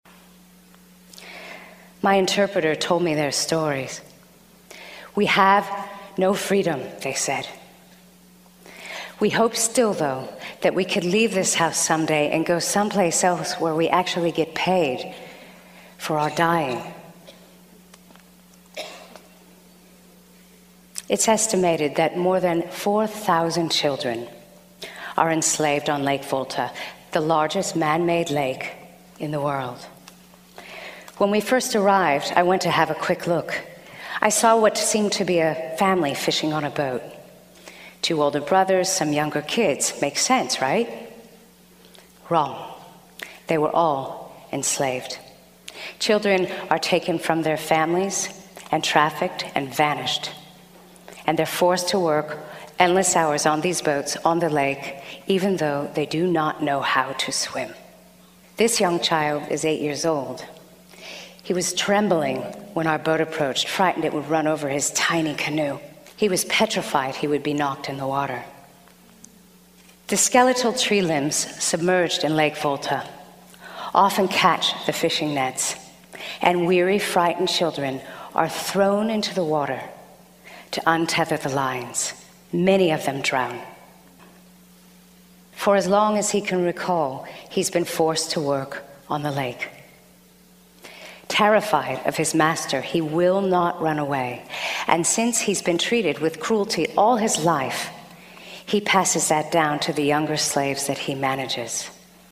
TED演讲:见证现代奴役的照片(7) 听力文件下载—在线英语听力室